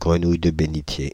Ääntäminen
Ääntäminen France (Île-de-France): IPA: /ɡʁə.nuj də be.ni.tje/ Haettu sana löytyi näillä lähdekielillä: ranska Käännöksiä ei löytynyt valitulle kohdekielelle.